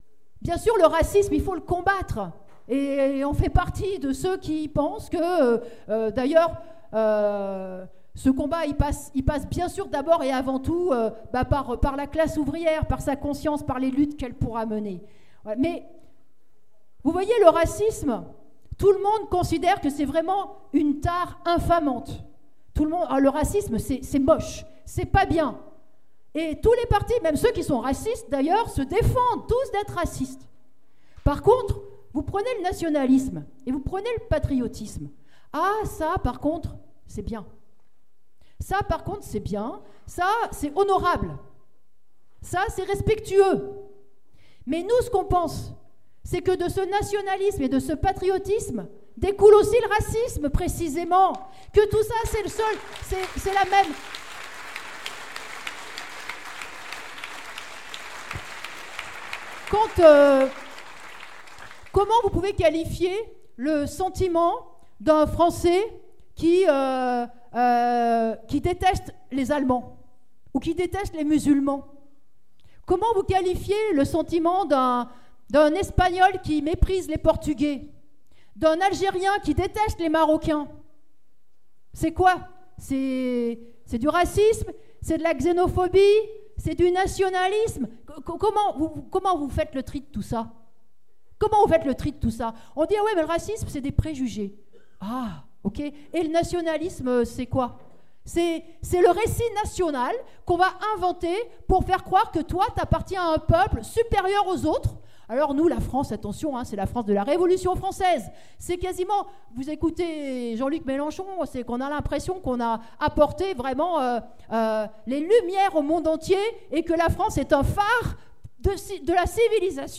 Nathalie Arthaud débat à la fête lyonnaise de LO : Combattre le racisme, et aussi le nationalisme